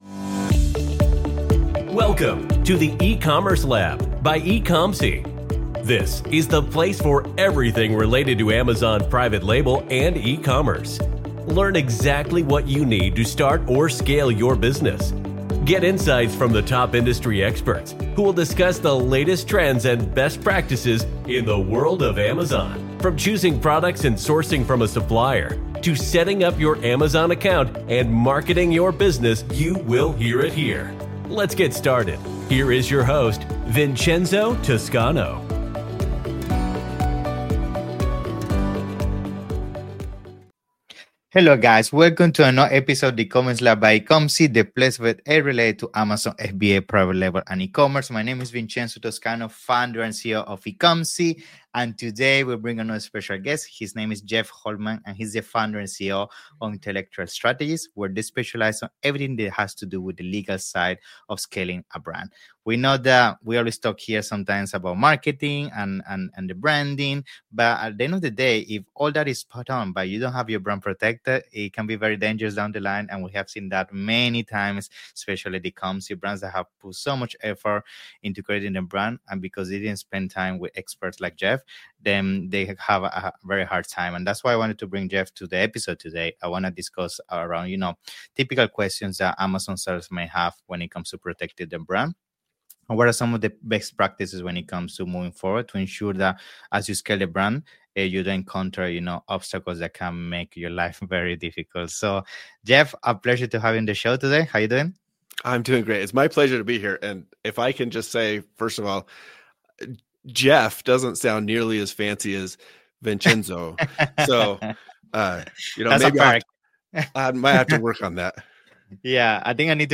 Welcome to the eCommerce Lab Podcast! Get ready for a fascinating conversation!